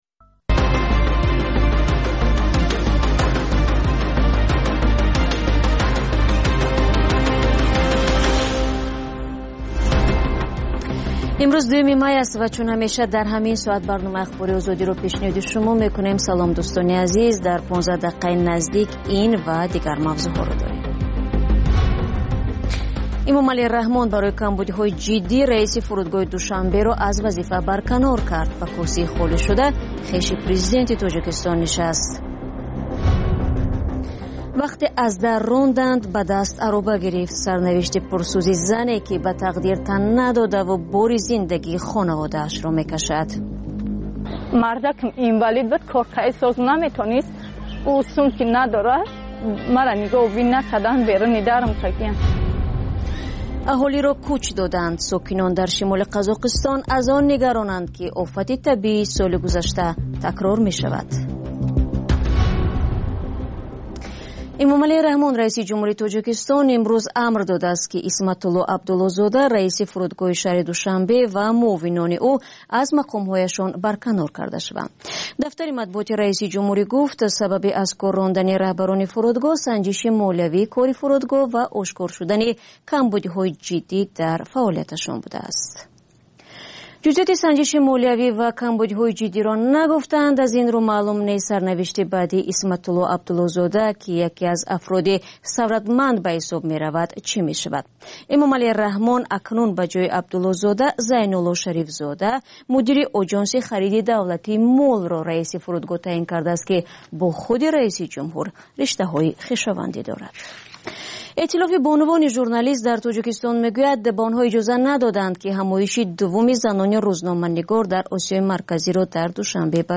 Пахши зинда